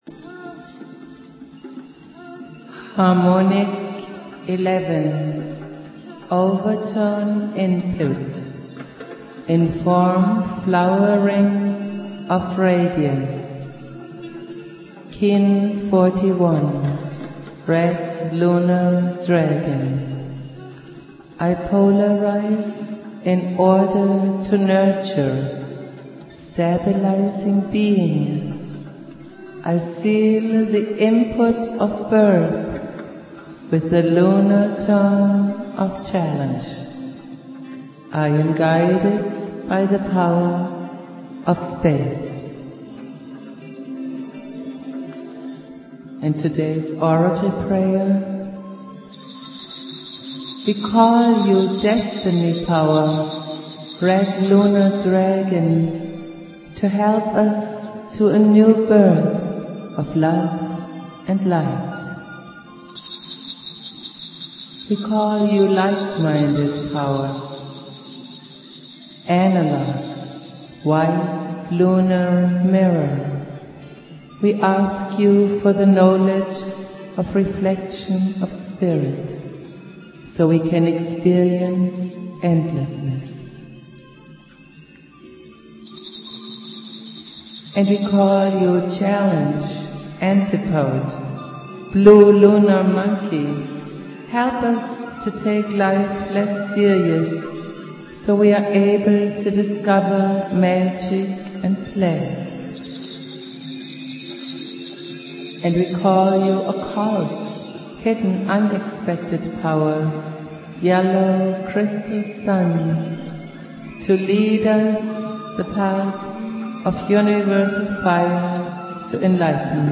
Prayer
Jose's spirit and teachings go on Jose Argüelles playing flute.